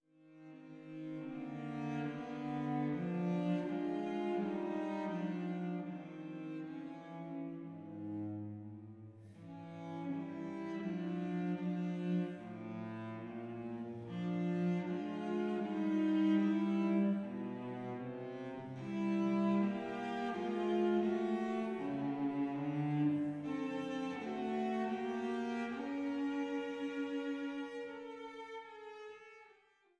Die DREI SUITEN FÜR VIOLONCELL ALLEIN op. 131c